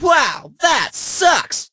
poco_kill_02.ogg